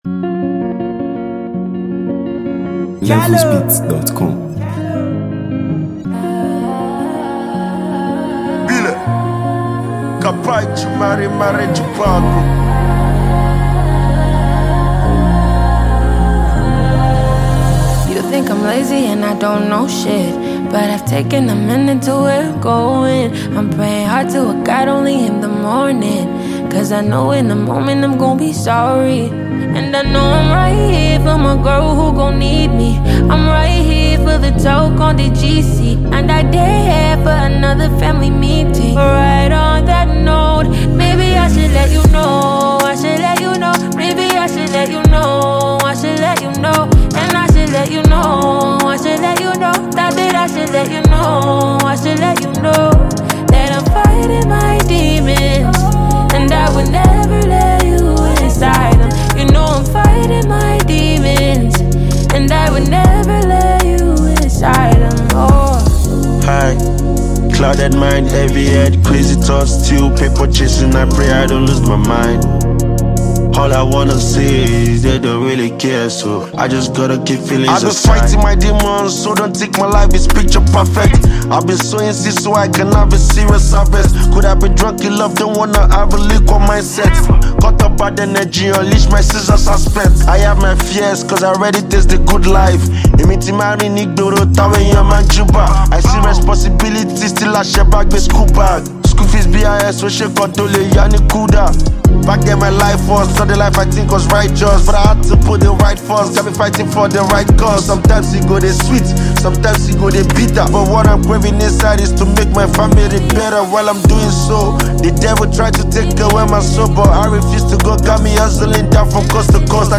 Nigeria Music 2025 3:34
a sensational female singer-songwriter